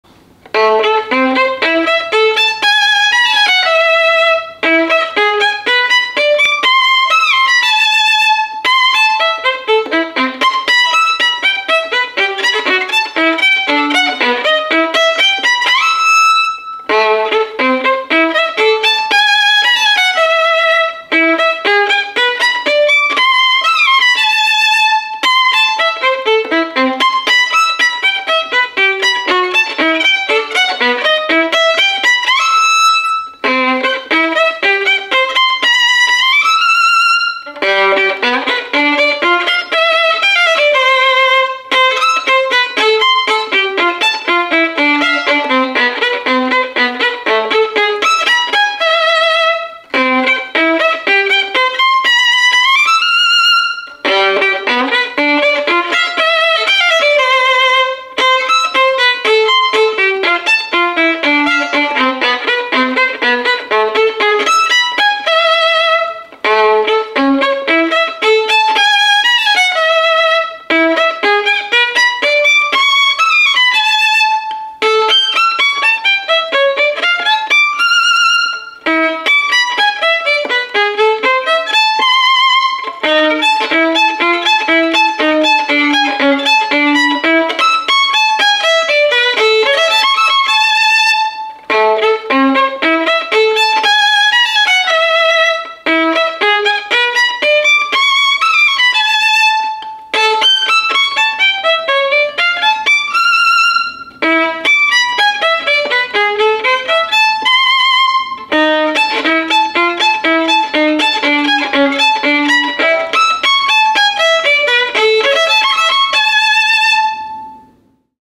Violinista-MAZAS-ETUDE-NO.10-FROM-ETUDES-SPECIALES-Op.36.mp3